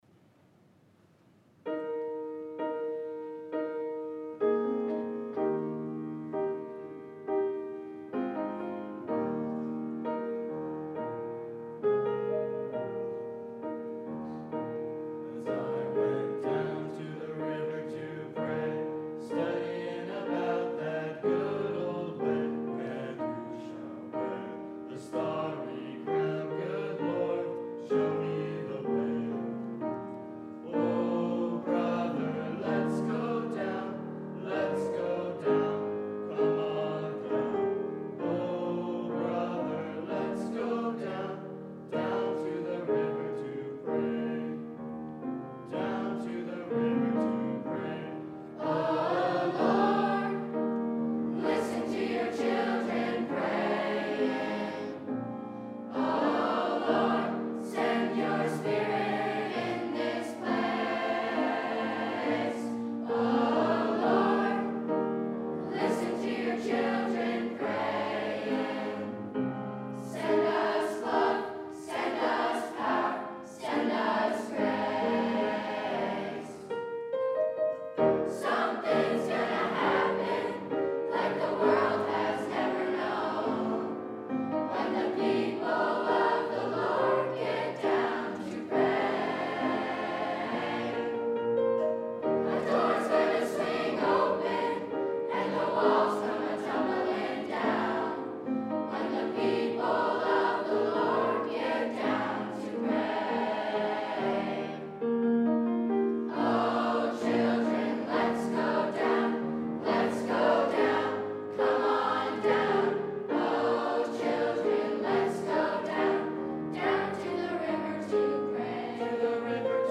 GVCA_Choir_October_Chapel_2025.mp3